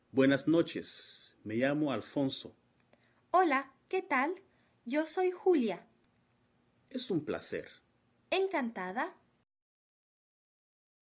Diálogo
dialogo.rm